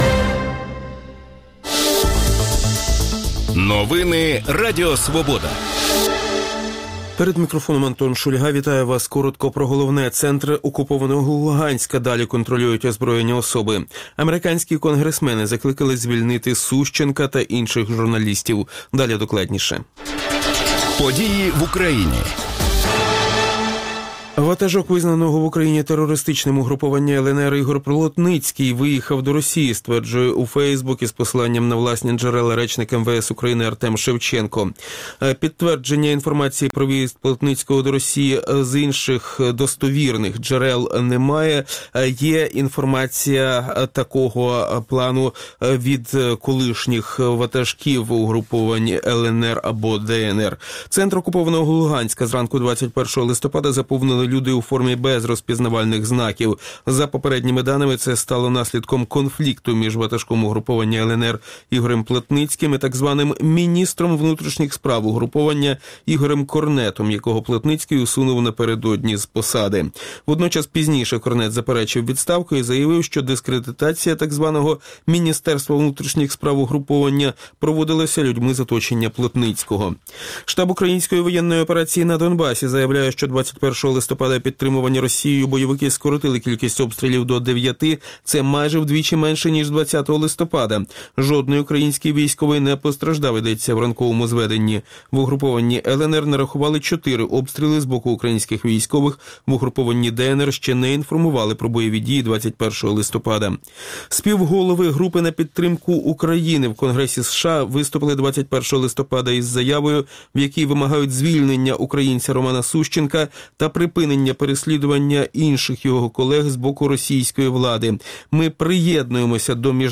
Це у Ранковій Свободі обговорюють народний депутат, голова комітету Верховної Ради у закордонних справах Ганна Гопко та надзвичайний і повноважний посол України в Республіці Білорусь (у 2007–2010 роках) Ігор Ліховий.